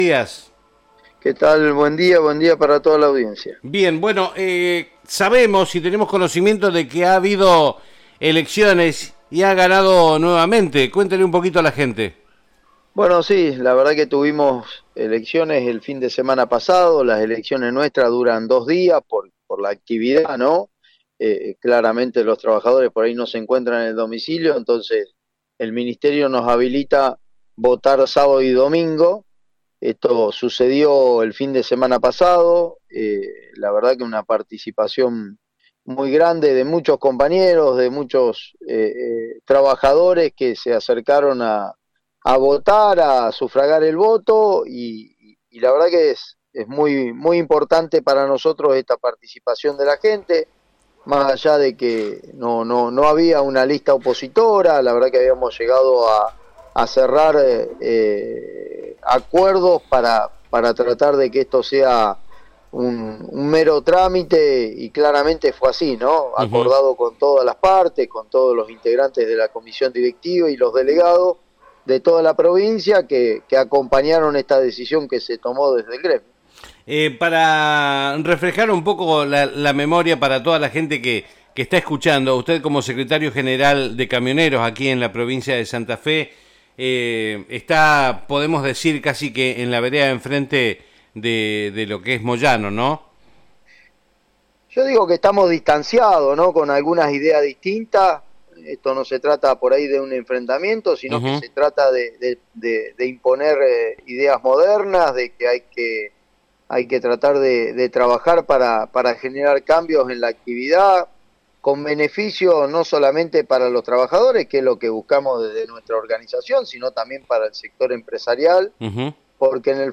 en comunicación telefónica